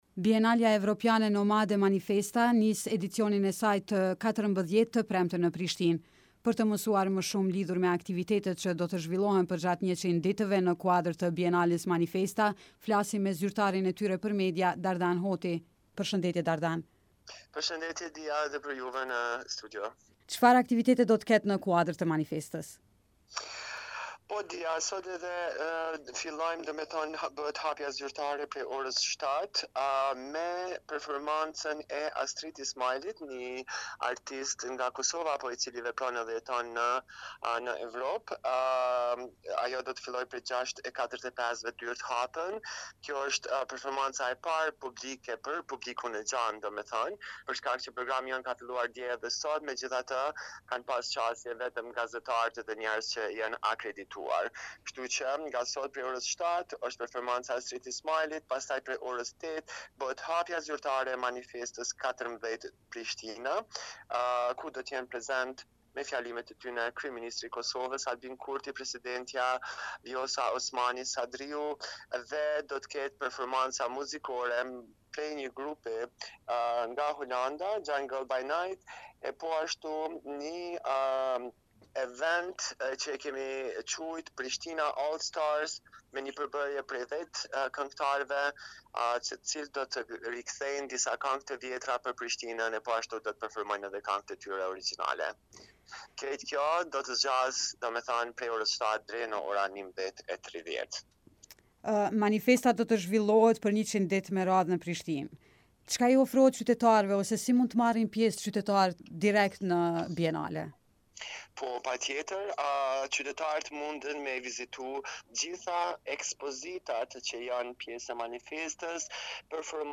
Në bisedën me Radion Evropa e Lirë